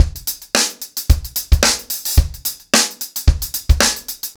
TupidCow-110BPM.25.wav